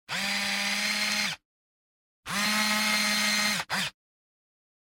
Звуки зума камеры
Эта коллекция включает различные варианты работы моторчика объектива: плавное и резкое приближение, отдаление, фокусировку.
Звук зуум наезд